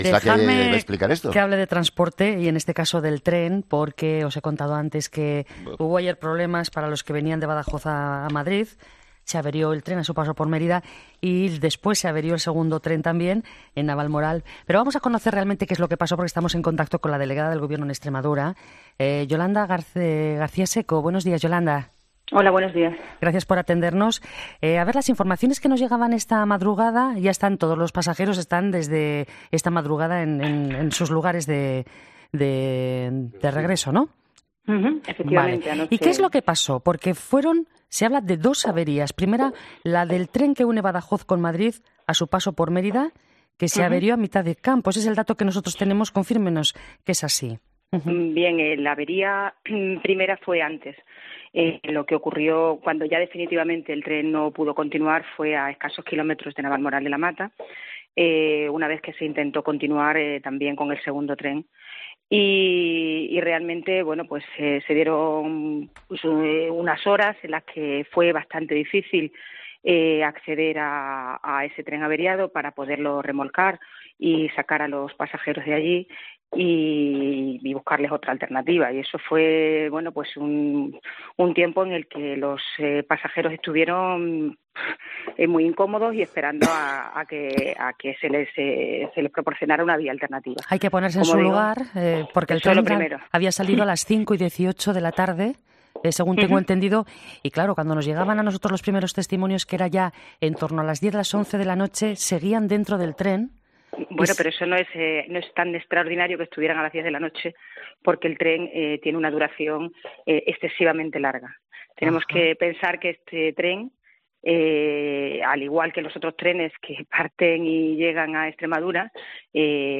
Yolanda García Seco, delegada del Gobierno en Extremadura, insiste en ‘Herrera en COPE’ en que “esto no puede volver a ocurrir, hay que acelerar las obras en las infraestructuras.